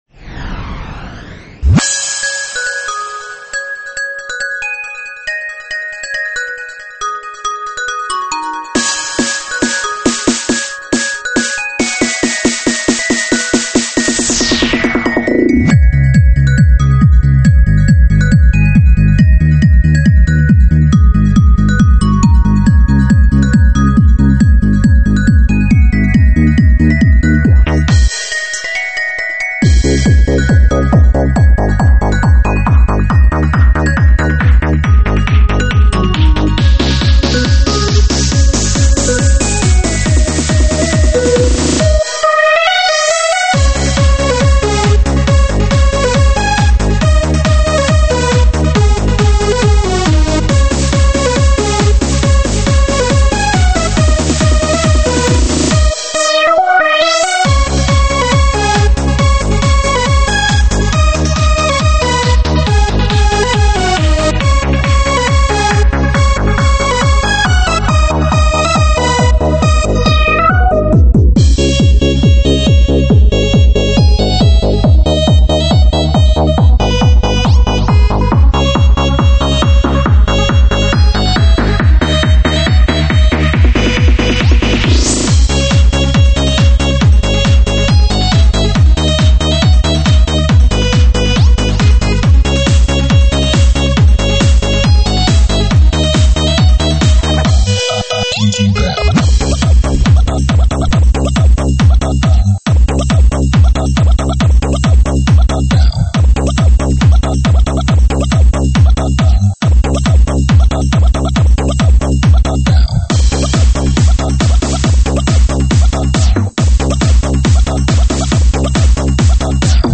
舞曲类别：3D全景环绕